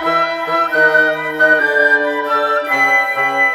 Rock-Pop 01 Winds 03.wav